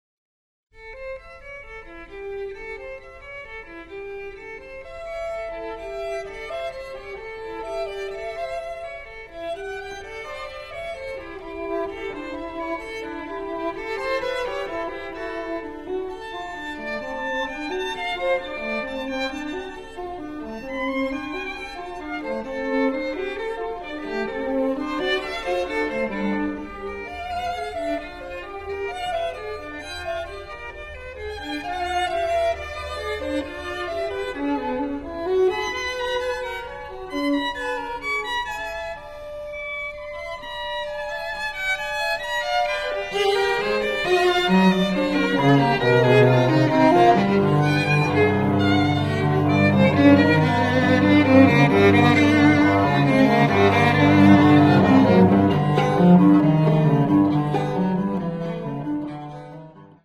Click here to hear a sample of my favourite piece of all of Ravel's, the allegro from the sonata for violin and cello, and click here to read an interesting quote I came across by him which I think says a lot about both him and his music.